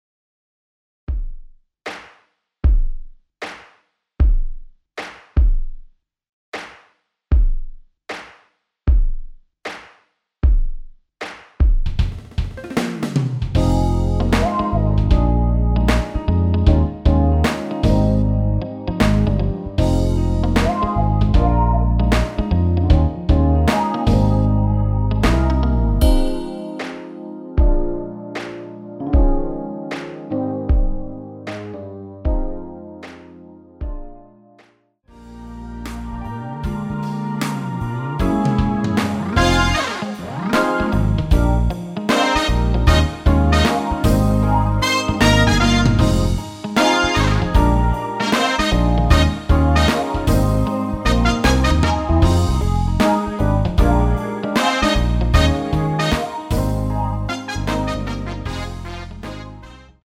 원키에서(+1)올린 MR입니다.
Ab
앞부분30초, 뒷부분30초씩 편집해서 올려 드리고 있습니다.